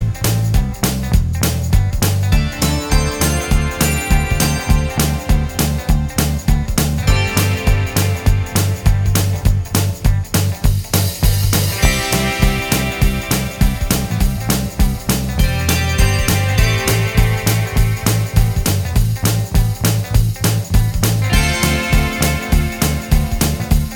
Minus Rhythm And Riff Guitars Pop (1980s) 3:49 Buy £1.50